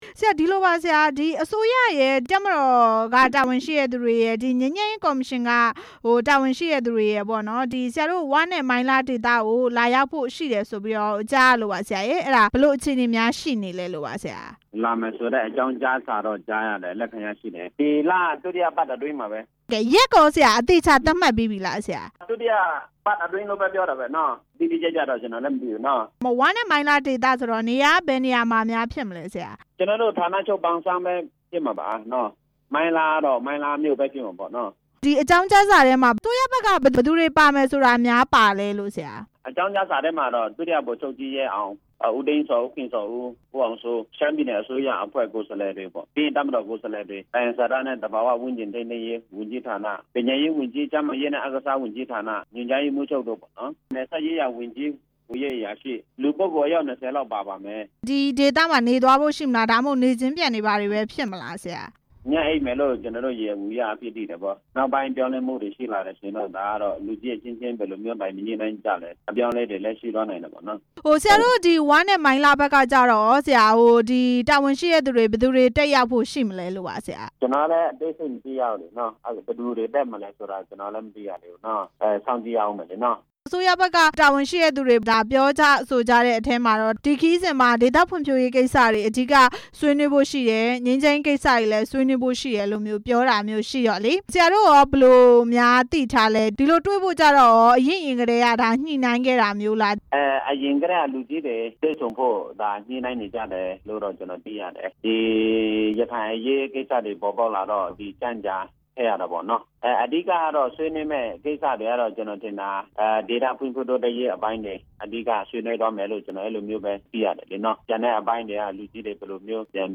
ငြိမ်းချမ်းရေးကော်မရှင်နဲ့ ဝ အဖွဲ့ တွေ့ဆုံမည့်အကြောင်း မေးမြန်းချက်